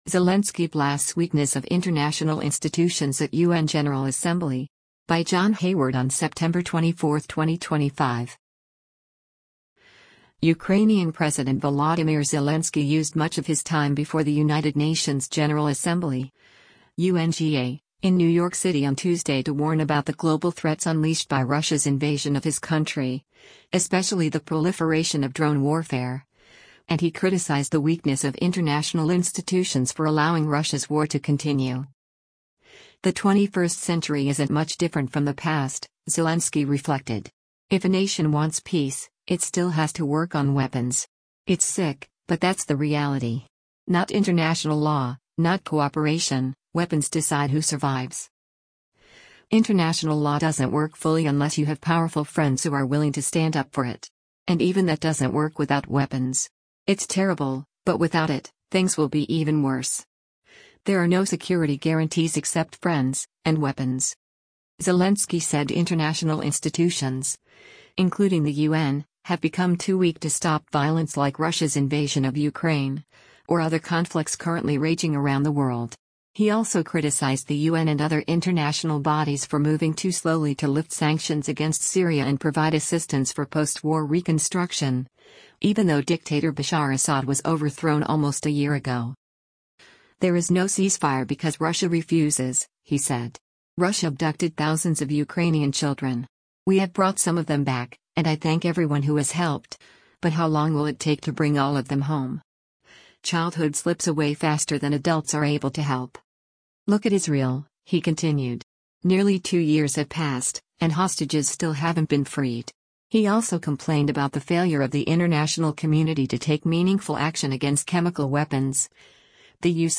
Zelensky Blasts Weakness of ‘International Institutions’ at U.N. General Assembly
Ukrainian President Volodymyr Zelensky used much of his time before the United Nations General Assembly (UNGA) in New York City on Tuesday to warn about the global threats unleashed by Russia’s invasion of his country, especially the proliferation of drone warfare, and he criticized the weakness of international institutions for allowing Russia’s war to continue.